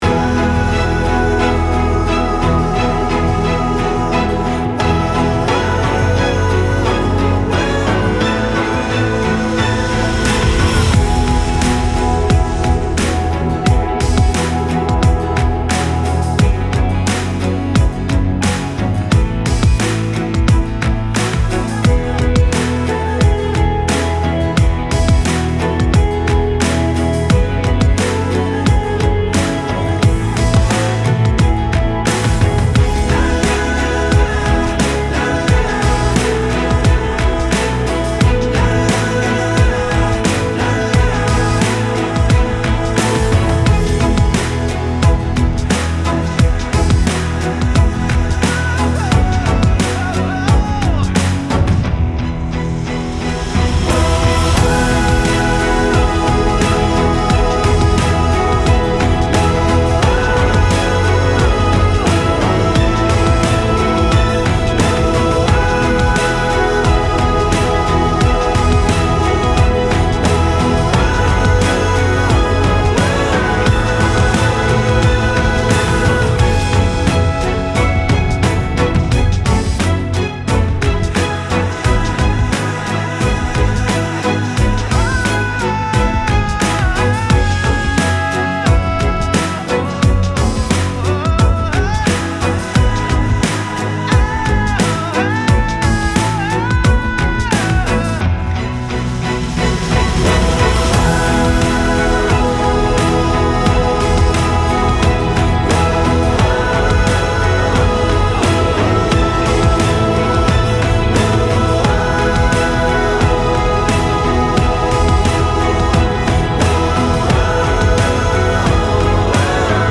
SONG ROCK / POPS